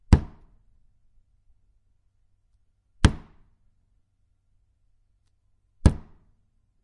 描述：经典邮票用于签署和盖章一些文件。
标签： 冲压 印花税
声道立体声